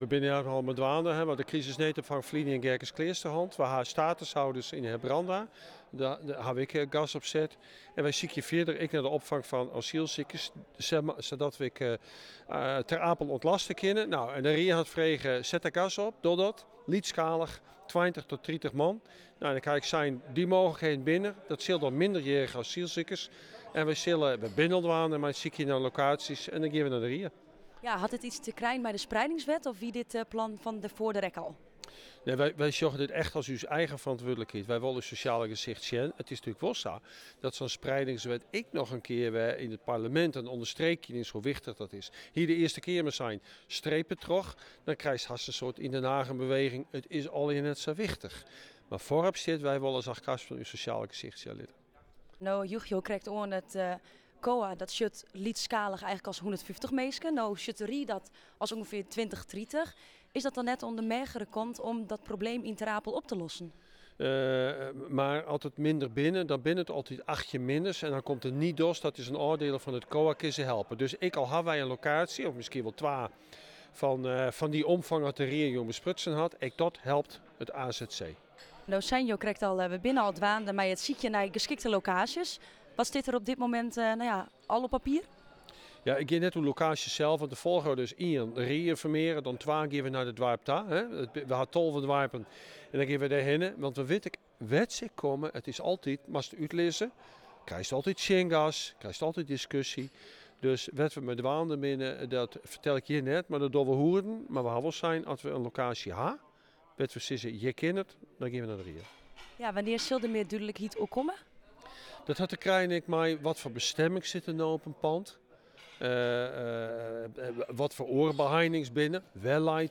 Portefeuillehouder Oebele Brouwer met een toelichting over de toekomst van het opvangen van asielzoekers in de gemeente: